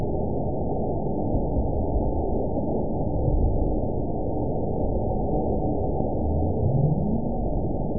event 917786 date 04/16/23 time 22:43:34 GMT (2 years, 1 month ago) score 8.93 location TSS-AB04 detected by nrw target species NRW annotations +NRW Spectrogram: Frequency (kHz) vs. Time (s) audio not available .wav